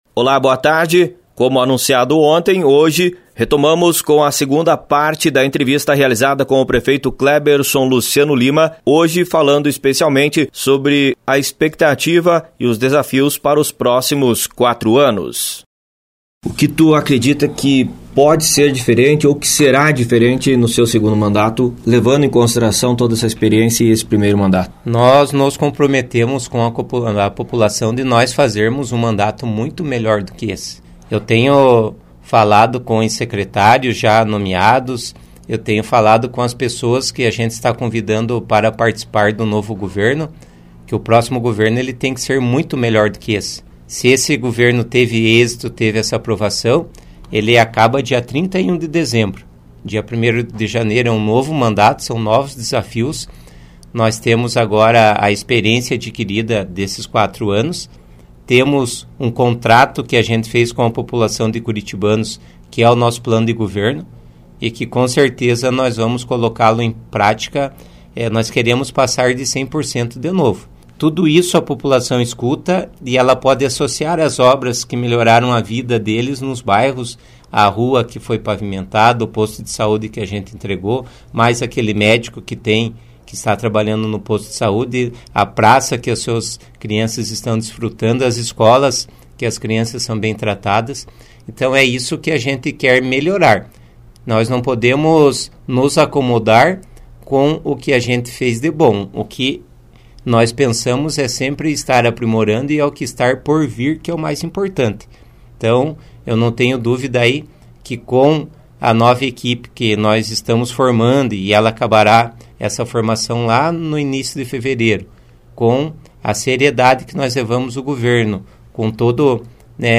Prefeito falou sobre as principais ações de seu primeiro mandato e da expectativa para o segundo mandato que inicia no próximo dia 01 de janeiro de 2025.